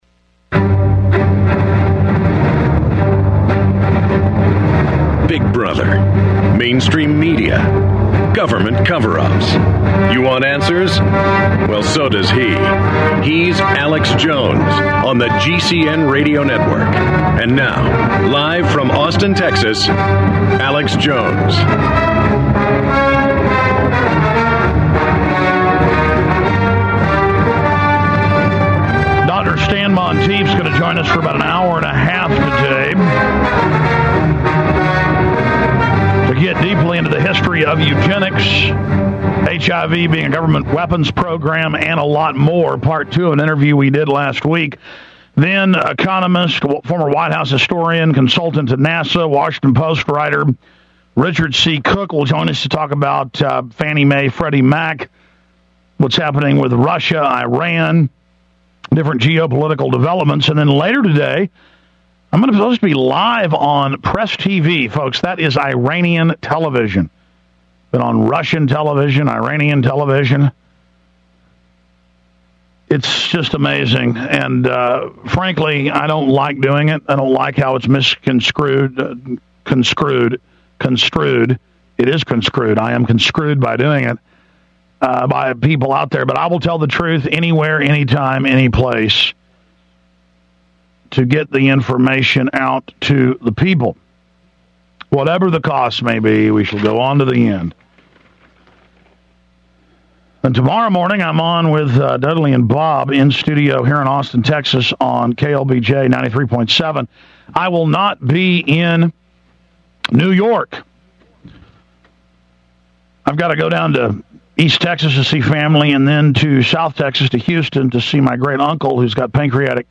Alex Jones Show Commercial Free Podcast